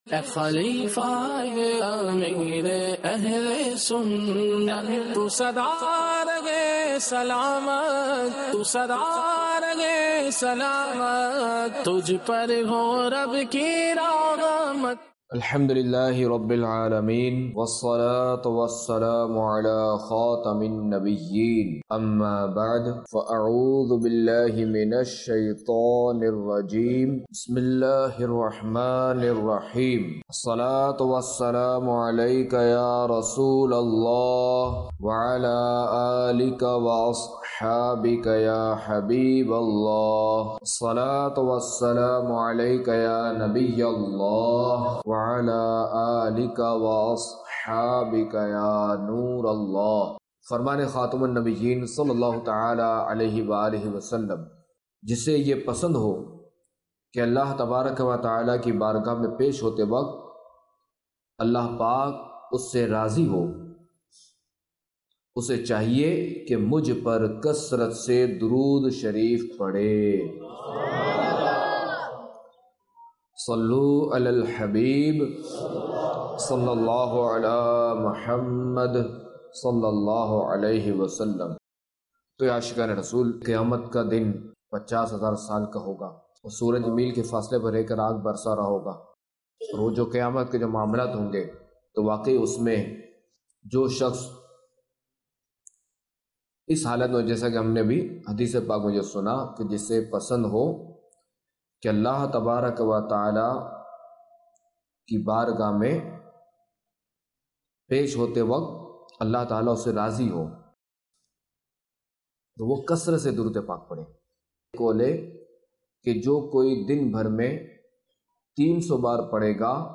خلیفہ امیر اہلسنت کے بیانات - مقصدِ حیات